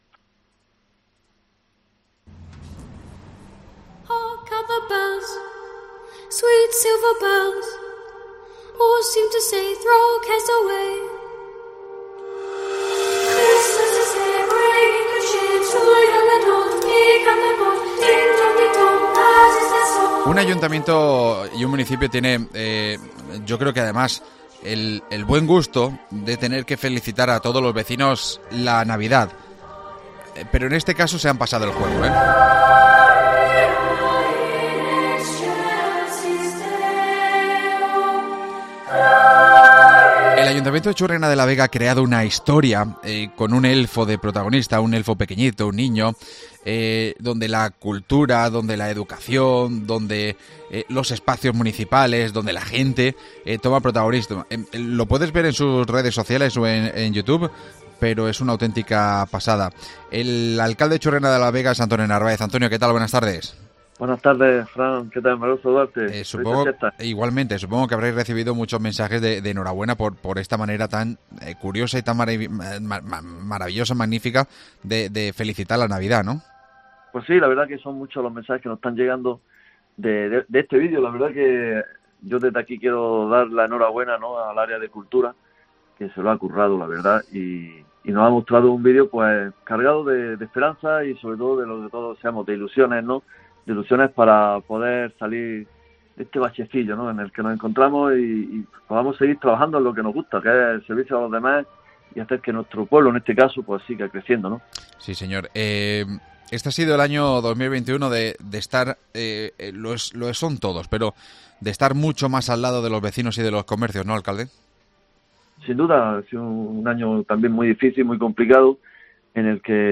AUDIO: Entrevistamos al alcalde del municipio, Antonio Narváez.